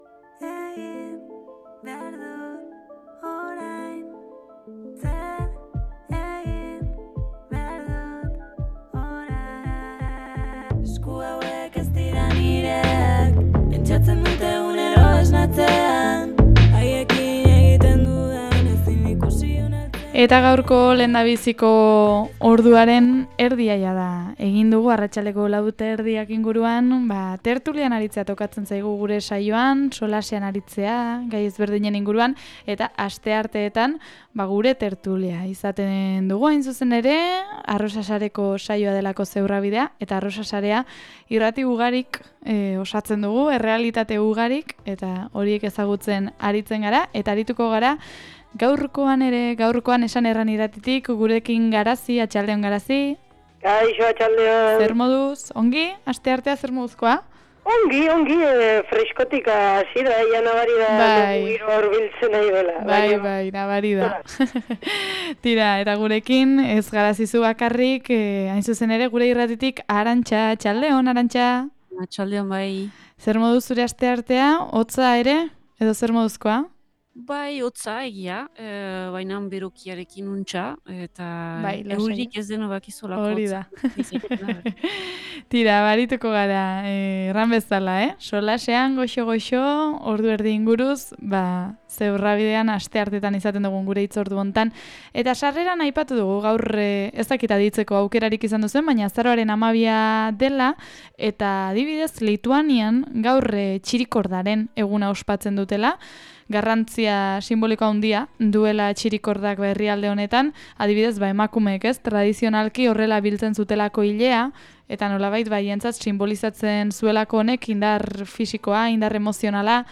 Gaurkoan gure tertuliaren tartean gai potolo bati heldu diogu: tradizioari.